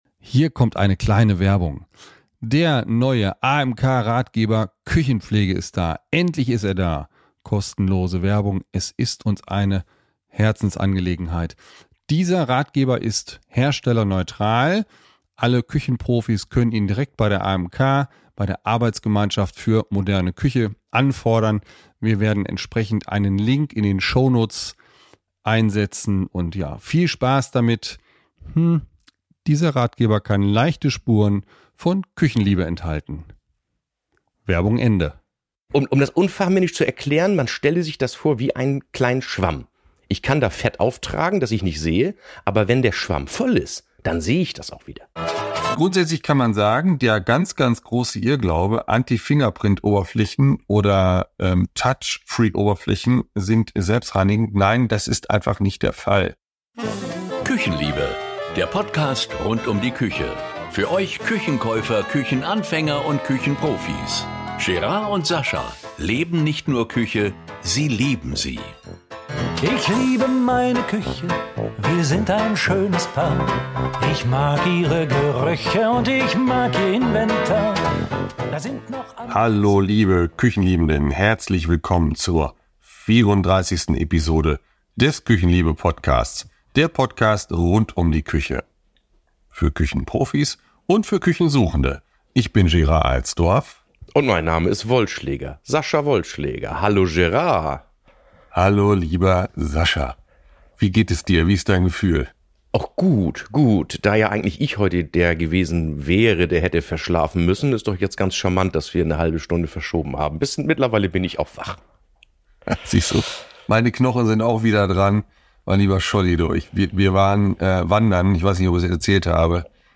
Die Küchenliebhaber reden über diese ultramatten Oberflächen, und wollen Irrtümer über die Eigenschaften des Materials aufklären.